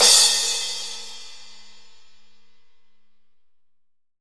Index of /90_sSampleCDs/AKAI S6000 CD-ROM - Volume 3/Crash_Cymbal1/15-18_INCH_AMB_CRASH
15AMB CRS1-S.WAV